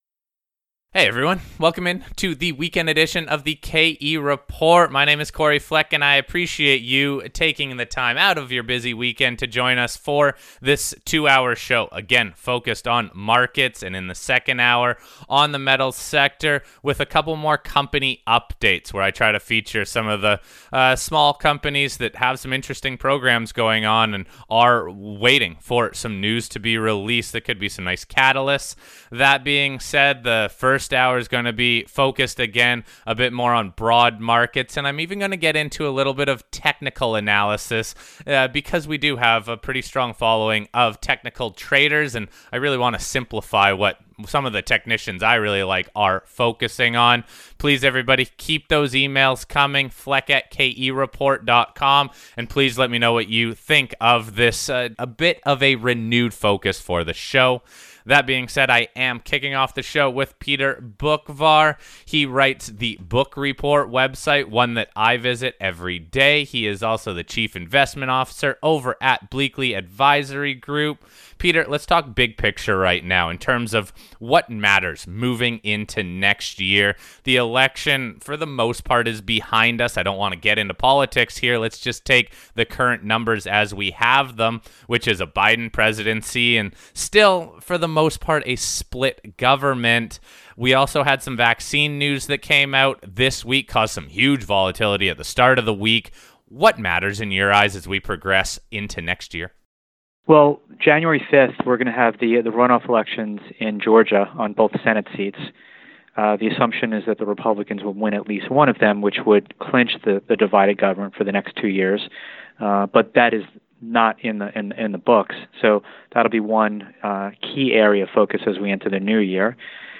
This is part 1 of a longer discussion that will be posted tomorrow morning and go live on radio stations around the US.